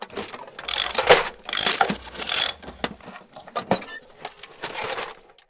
repair.ogg